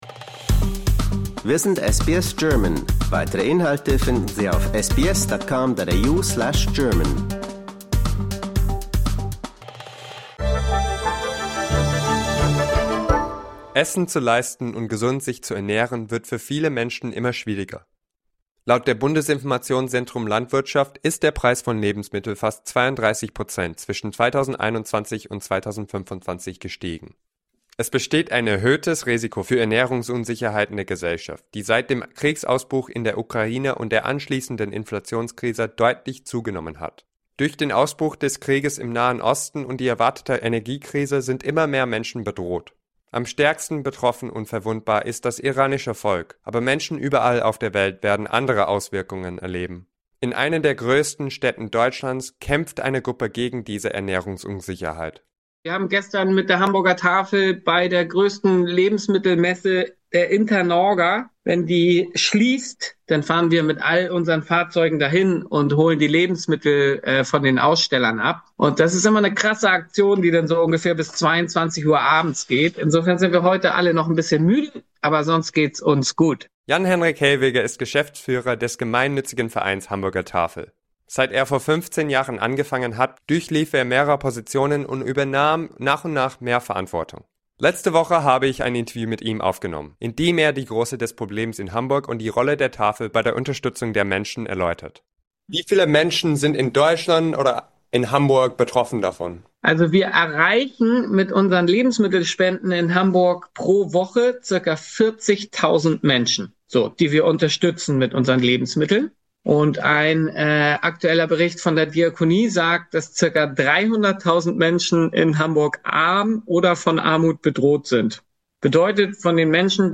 Disclaimer: We would like to point out that the opinions expressed in this article represent the personal views of the interviewed/interlocutor.